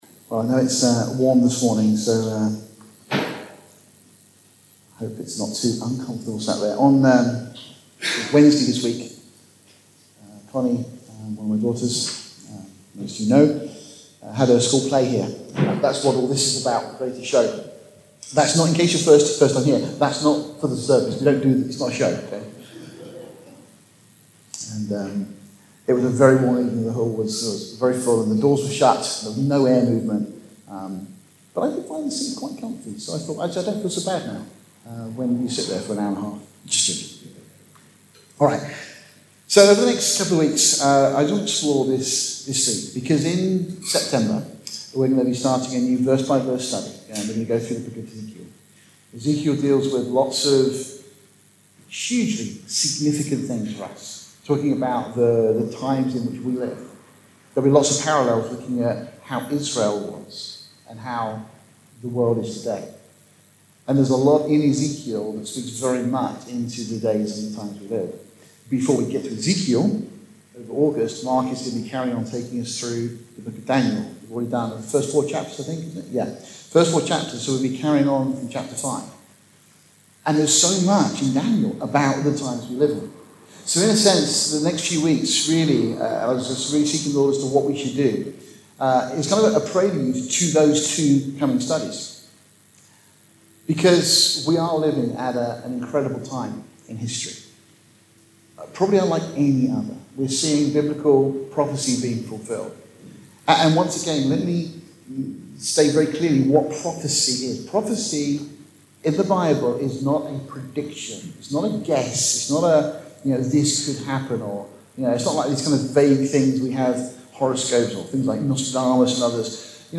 PS 1 ::::: The audio file has some moments when the recording was unclear. Efforts have been made to resolve this and the recording has been improved. There are, however, some words that are not unambiguously discernable.